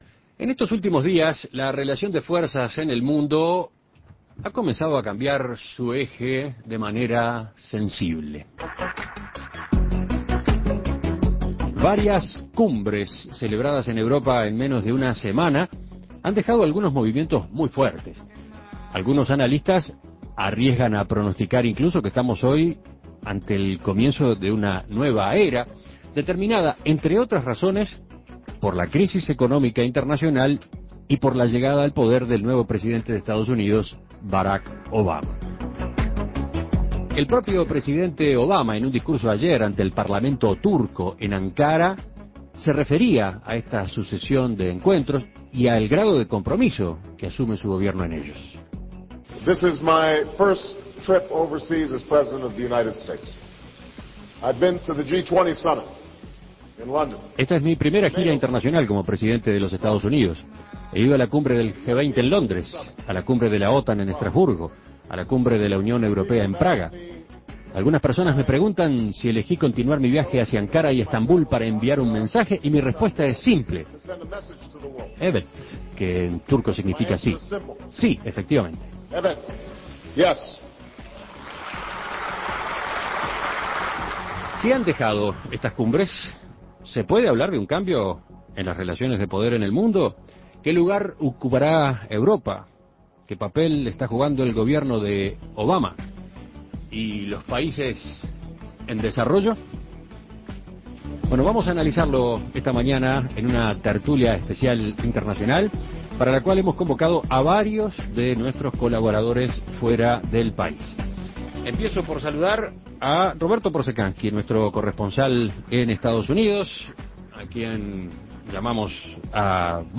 Tertulia internacional sobre los resultados de las últimas cumbres (G-20 y OTAN) y la presentación de Barack Obama en el escenario mundial